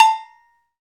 PRC XAGOGO09.wav